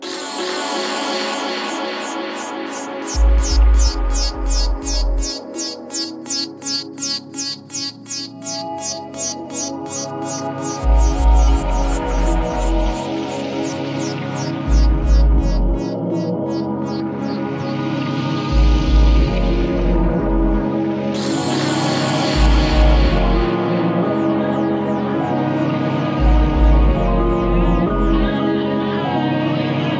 多彩なグルーヴやオーガニックでタッチでオーセンティックなディープ・ハウスの魅力を醸し、モダンなセンスで洗練された意欲作。